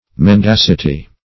mendacity - definition of mendacity - synonyms, pronunciation, spelling from Free Dictionary
Mendacity \Men*dac"i*ty\, n.; pl. Mendacities. [L.